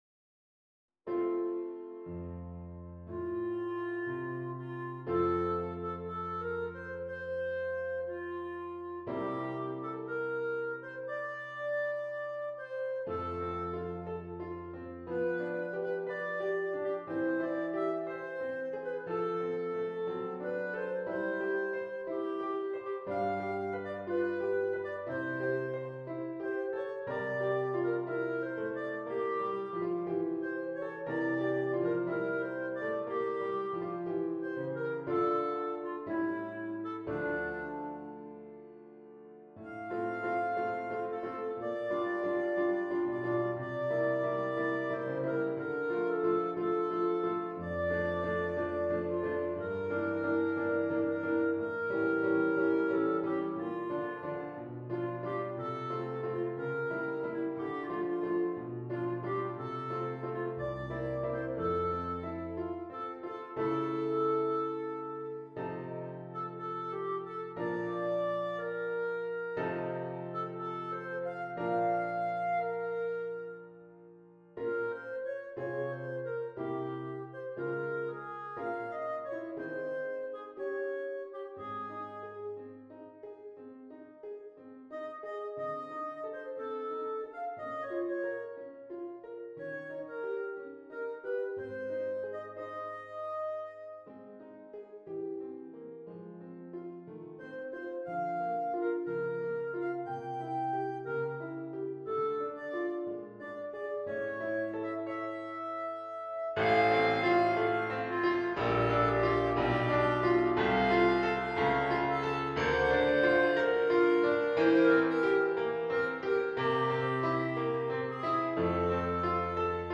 This file contains a full performance, accompaniment, and Bb clarinet sheet music for Schubert's Lieder Emma.